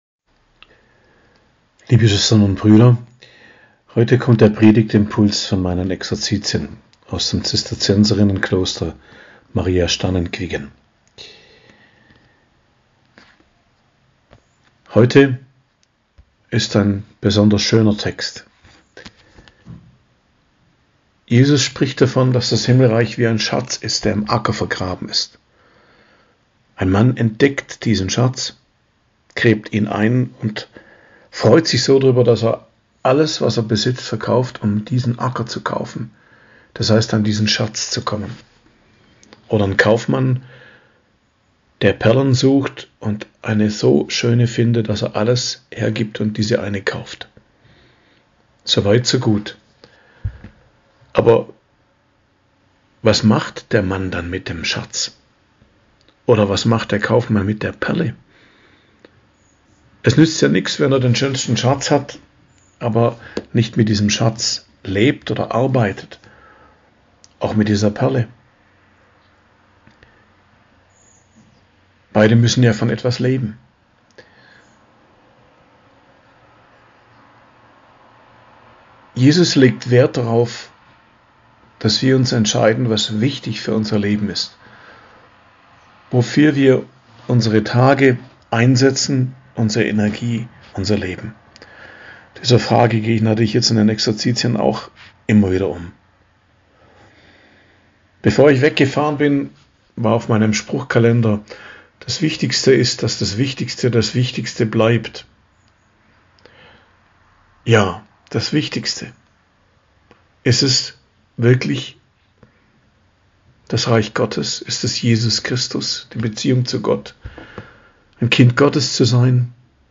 Predigt am Mittwoch der 17. Woche i.J., 27.07.2022